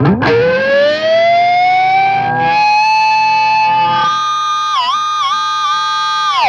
DIVEBOMB22-R.wav